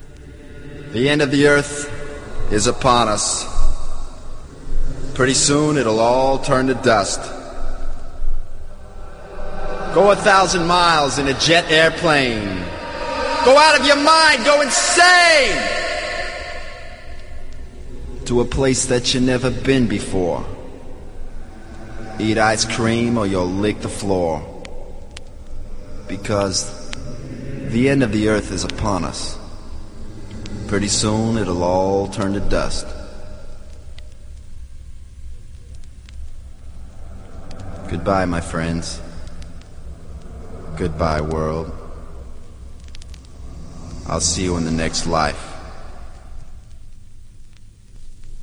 emotial-spoken-voice-the-end-of-the-world.wav